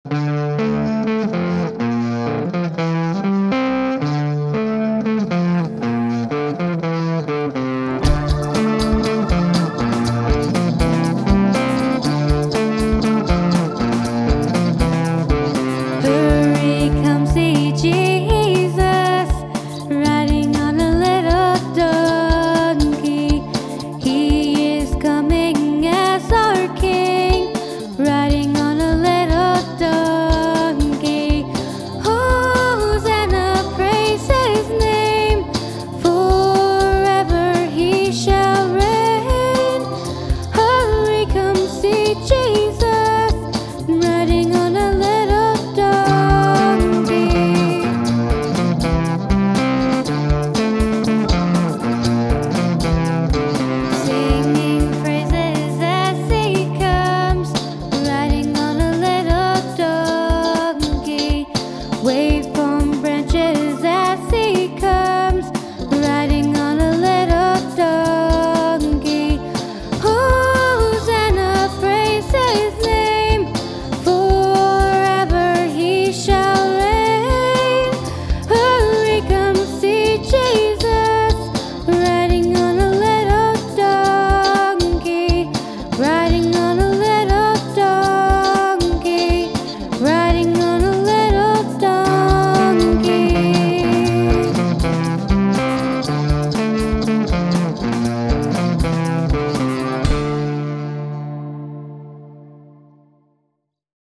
(Audio Available) Kids celebrate the day Jesus came riding into Jerusalem on a little donkey. Sung to the tune, “Nothing But the Blood of Jesus.”